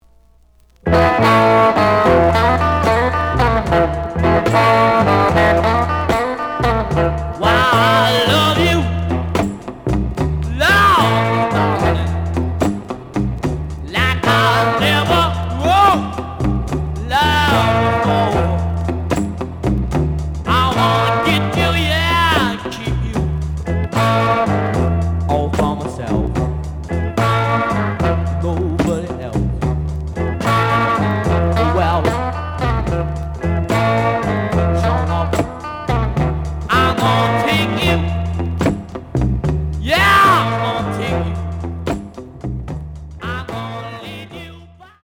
The audio sample is recorded from the actual item.
●Genre: Rock / Pop
B side plays good.)